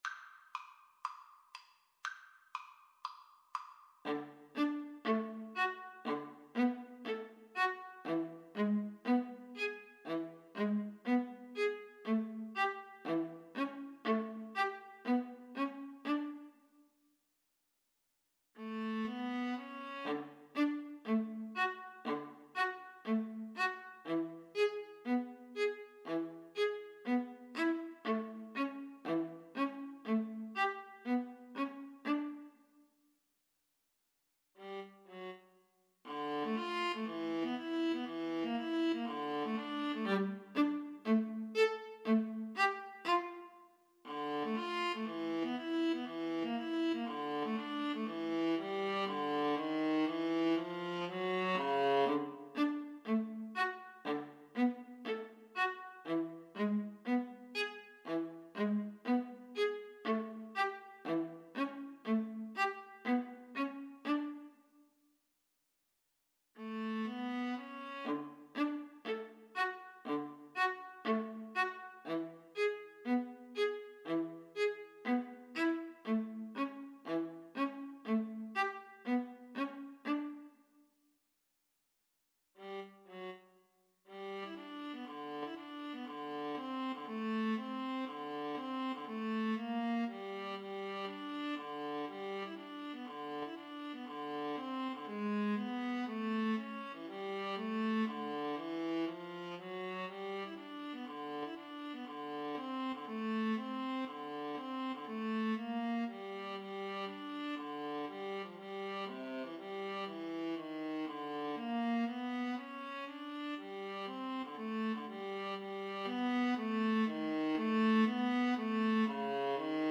Jazz (View more Jazz Viola Duet Music)
Rock and pop (View more Rock and pop Viola Duet Music)